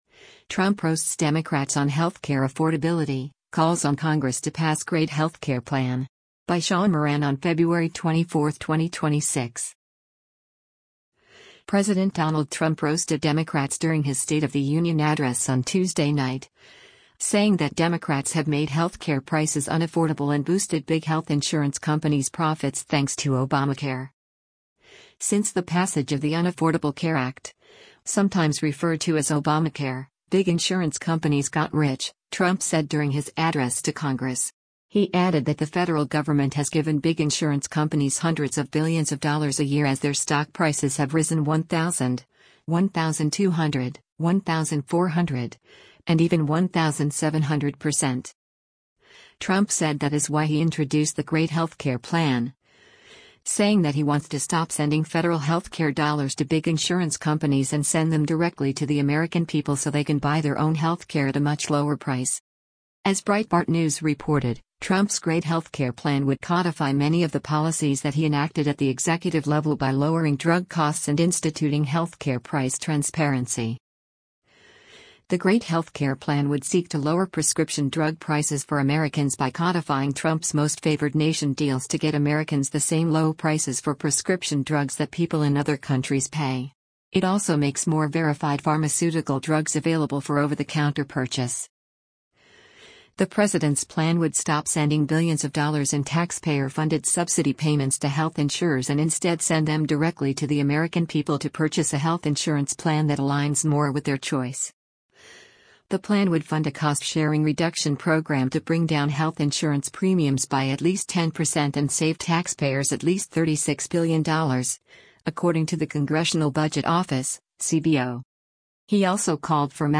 President Donald Trump roasted Democrats during his State of the Union address on Tuesday night, saying that Democrats have made healthcare prices unaffordable and boosted big health insurance companies’ profits thanks to Obamacare.